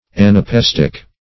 Anapaest \An`a*p[ae]st\, Anapaestic \An`a*p[ae]s"tic\